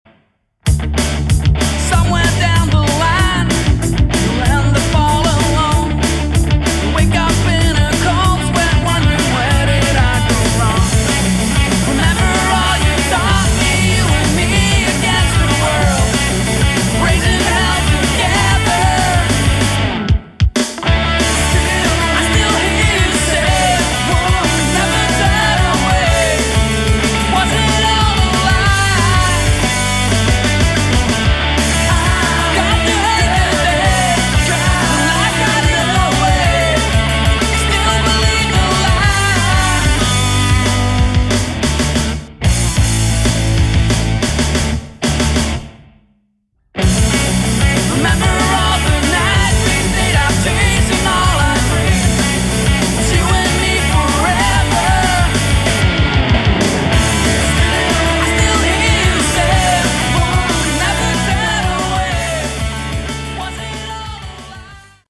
Category: Melodic Rock
Bass
Lead Vocals, Guitar
Drums
Keyboards
June 2007 Demo